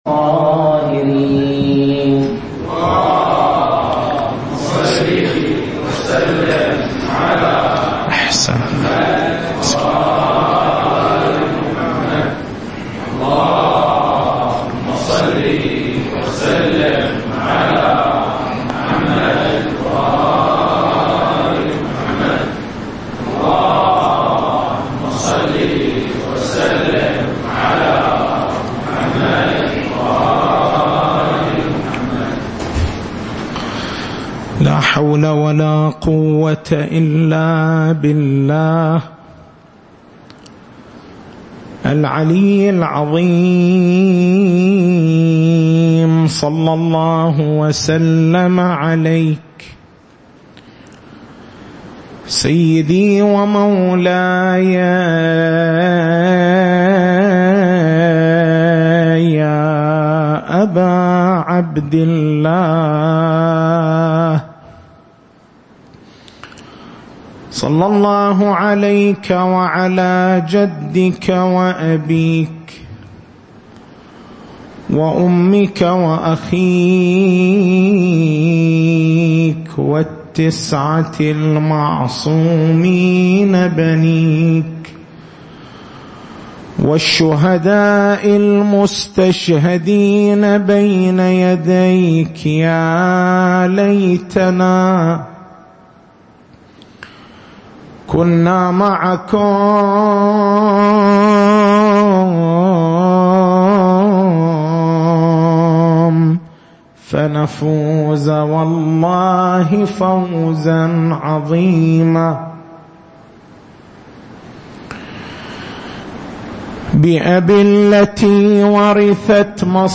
تاريخ المحاضرة: 12/09/1437 نقاط البحث: شروط العقد ما هو المقصود من شروط العقد؟ متى يكون شرط العقد لازمًا؟